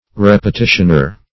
Repetitioner \Rep`e*ti"tion*er\ (-?r)